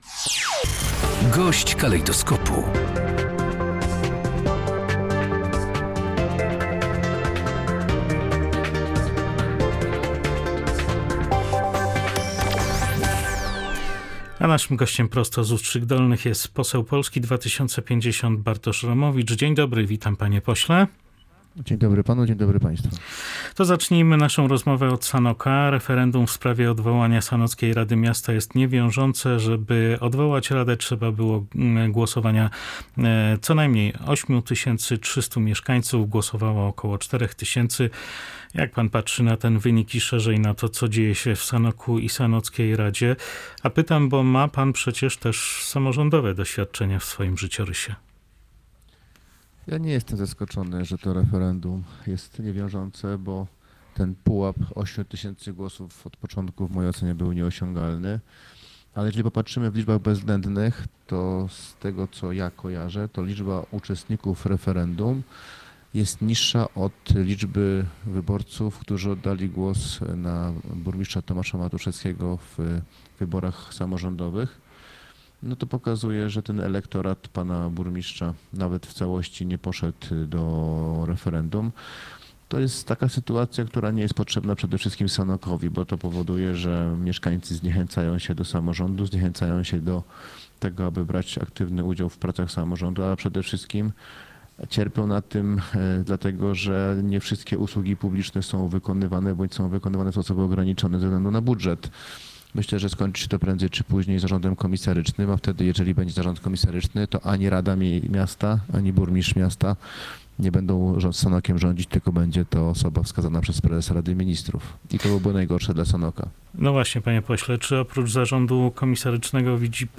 Gość dnia • Poseł Polski 2050 Bartosz Romowicz w rozmowie z Polskim Radiem Rzeszów ocenił sytuację w Sanoku po niewiążącym referendum, skomentował
Poseł Polski 2050 Bartosz Romowicz w rozmowie z Polskim Radiem Rzeszów ocenił sytuację w Sanoku po niewiążącym referendum, skomentował postępowania dotyczące Zbigniewa Ziobry i Marcina Romanowskiego oraz przedstawił perspektywy dla Polski 2050 i marszałka Szymona Hołowni.